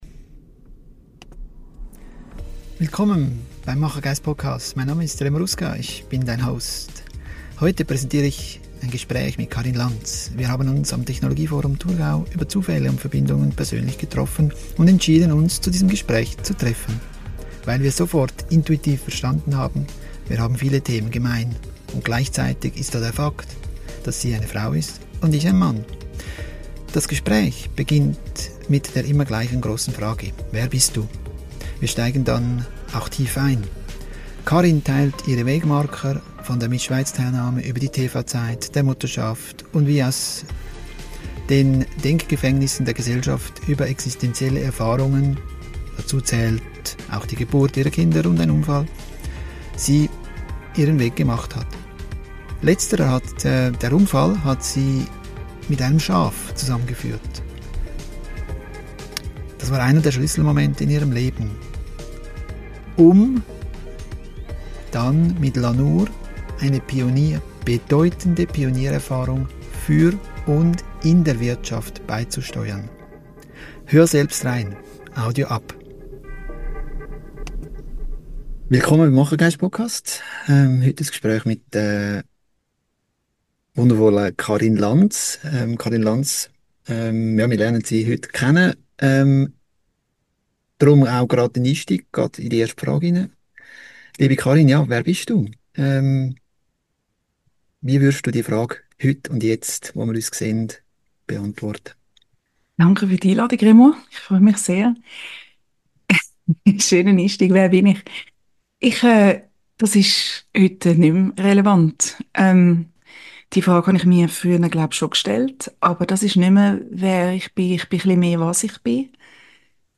Ein tiefes Gespräch.